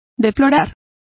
Complete with pronunciation of the translation of bewail.